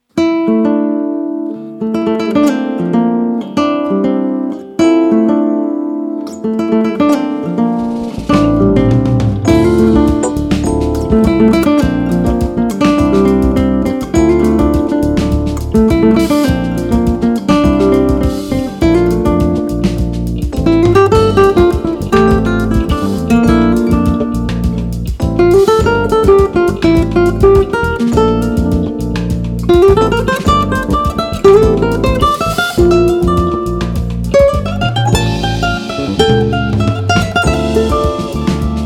Flamenco guitar, percussion, las palmas, bass, taconeos.
bossa rumba.
The bass participation is very lucid.